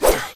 Sword_02.ogg